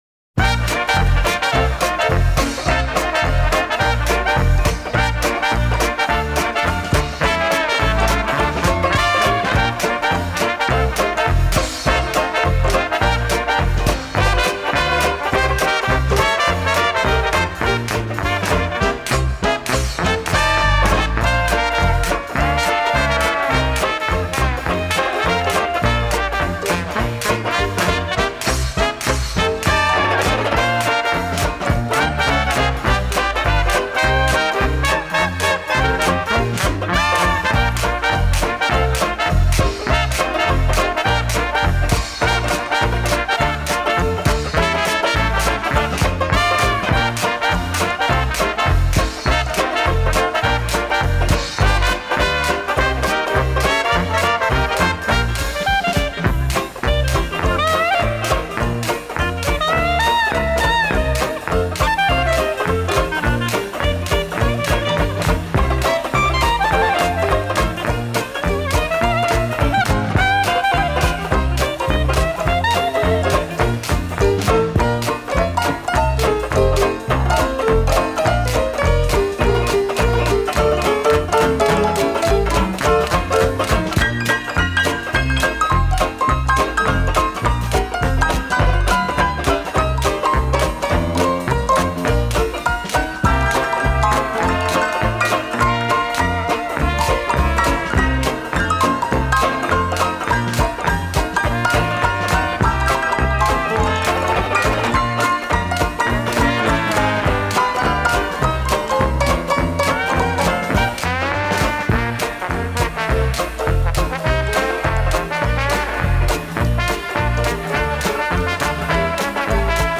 Дикси 2